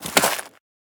File:Sfx creature snowstalkerbaby walk 04.ogg - Subnautica Wiki
Sfx_creature_snowstalkerbaby_walk_04.ogg